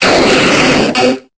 Cri de Krabboss dans Pokémon Épée et Bouclier.